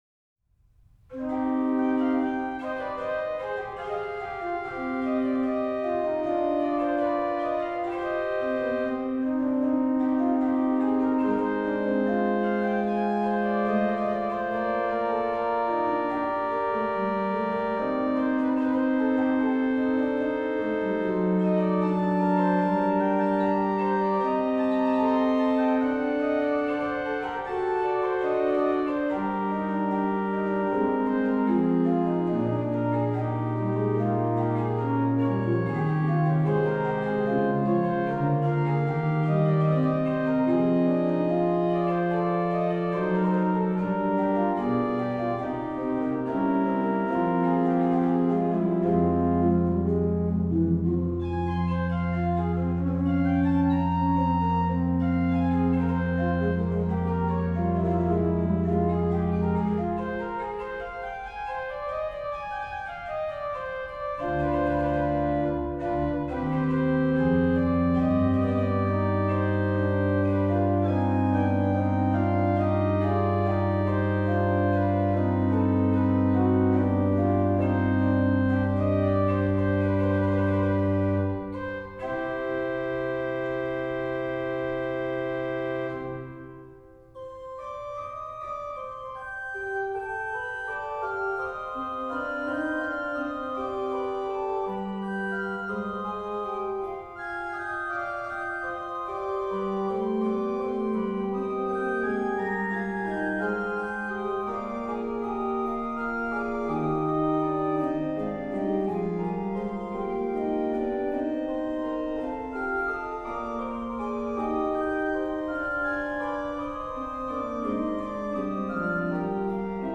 Registration   HW: Pr8
Ped: Pr 16 (8ve higher)
OW: Ged8, Rfl4 (Silbermann’s “Flöthen-Zug”)